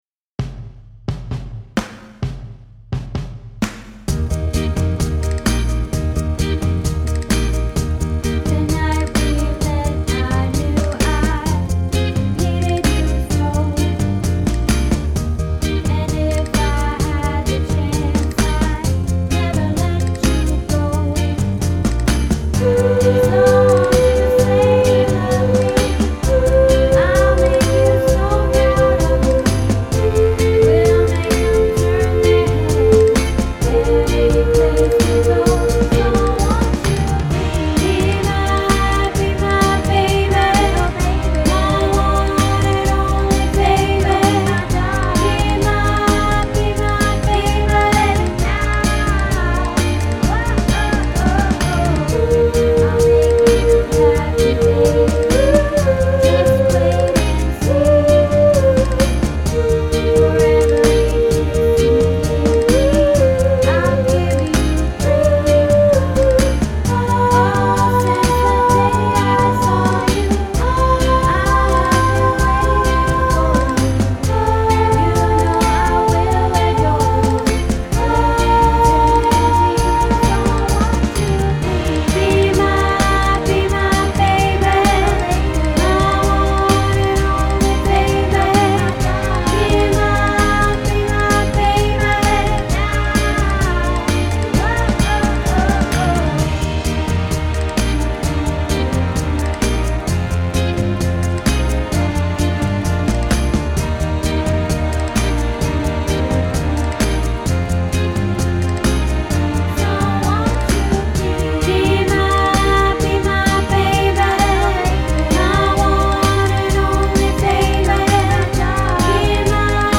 Be My Baby - Soprano